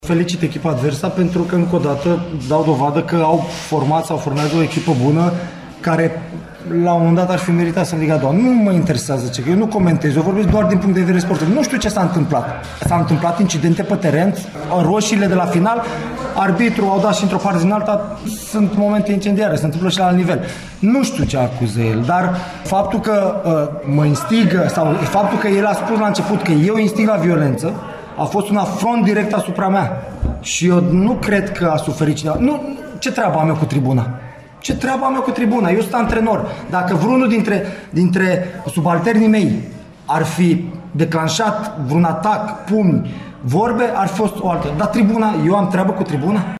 a fost extrem de revoltat la final de meci